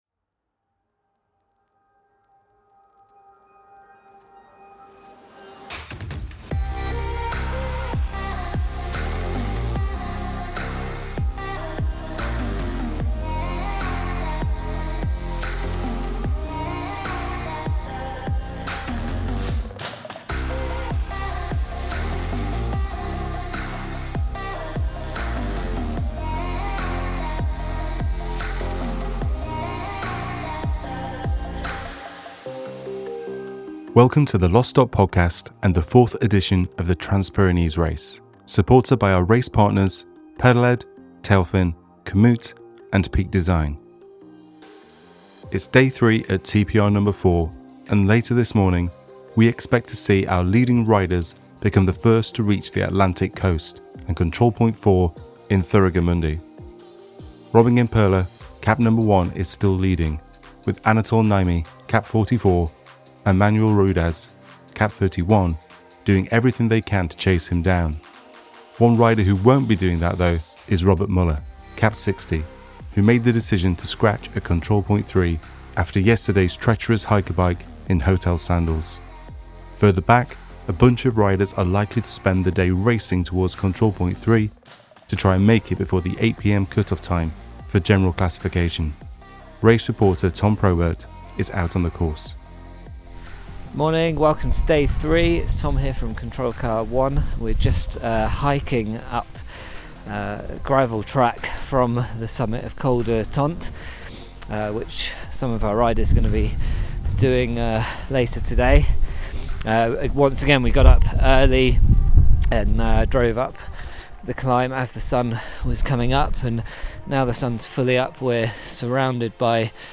Hear their determined voices. Back at Gavarnie, many riders are continuing to head over the trails towards Torla Ordesa.